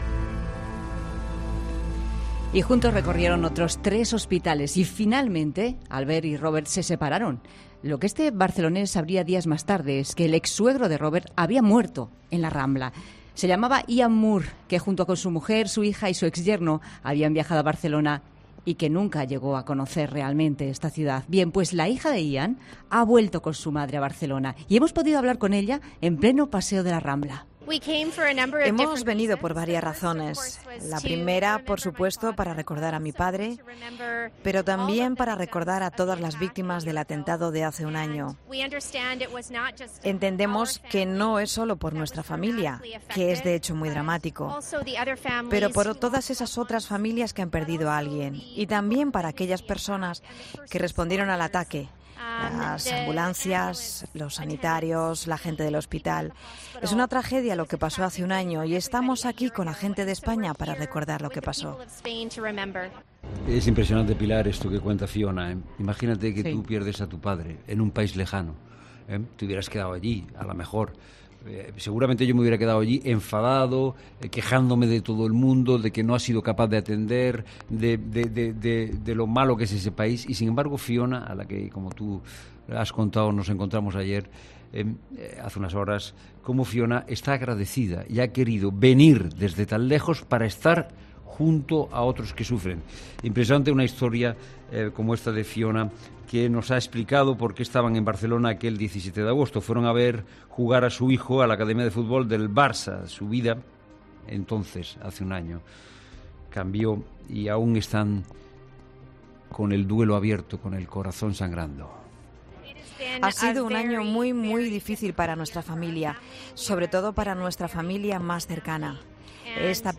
Hemos podido hablar con ella en pleno paseo de La Rambla...